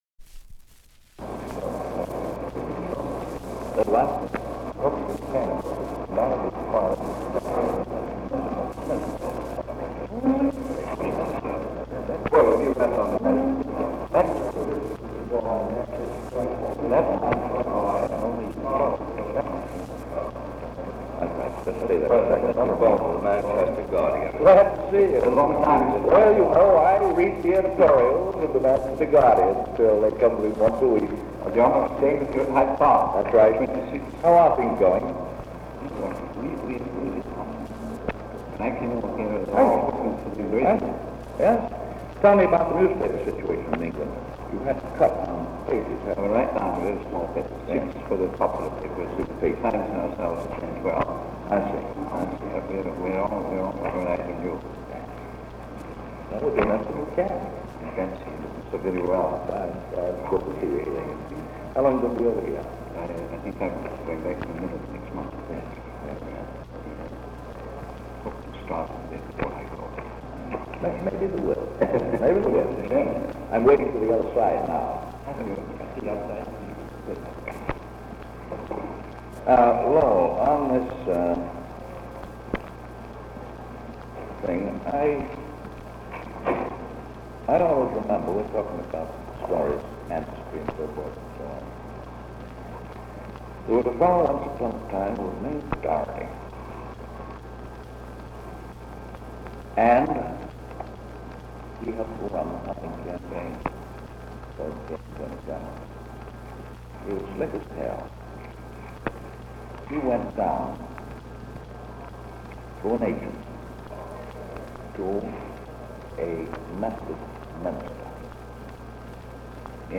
Office Conversation
Secret White House Tapes | Franklin D. Roosevelt Presidency